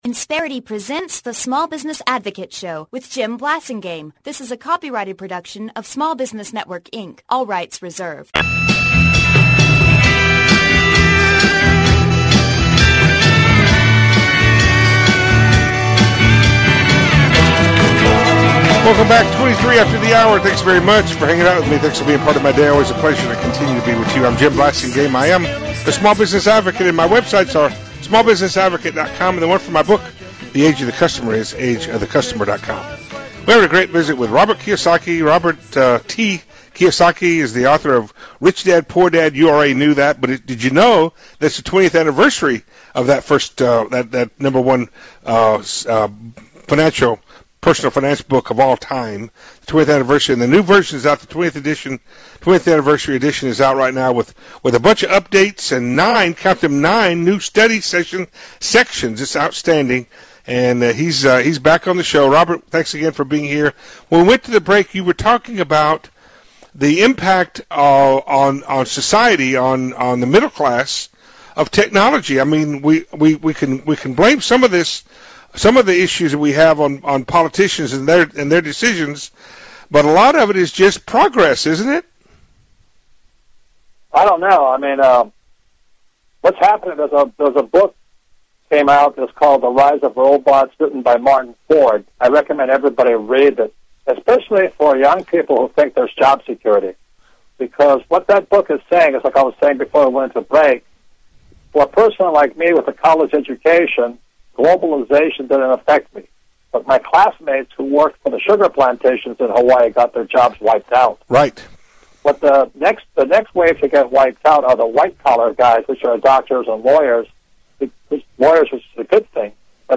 Archived Interviews